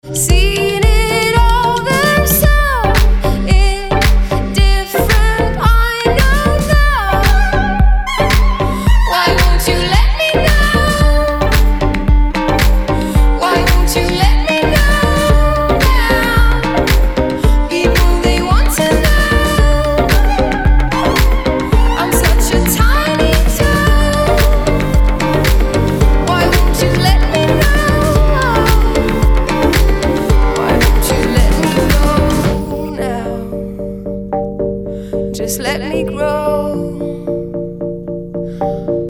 • Качество: 320, Stereo
женский вокал
dance
EDM
nu disco
красивый женский голос
Indie Dance
Очень красивая Indie Dance музыка и прекрасный вокал!